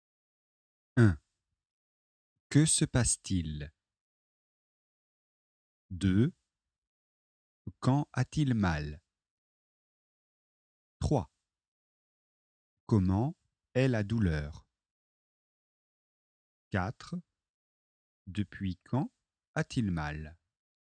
Dialogue chez le docteur - douleurs à l'estomac